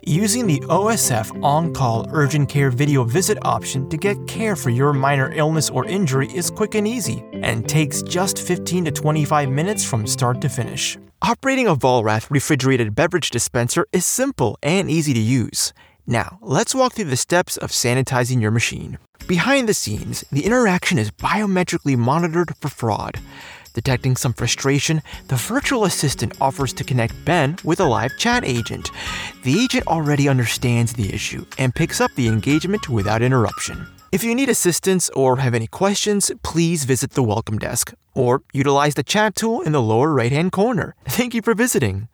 Teenager, Young Adult, Adult
Has Own Studio
e-learning